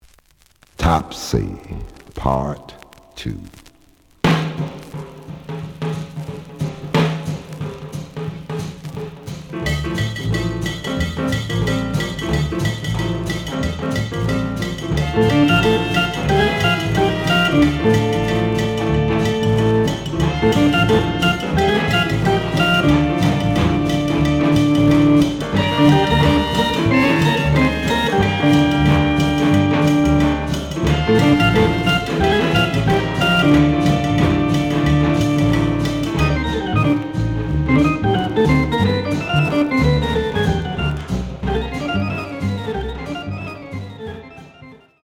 The audio sample is recorded from the actual item.
●Format: 7 inch
●Genre: Big Band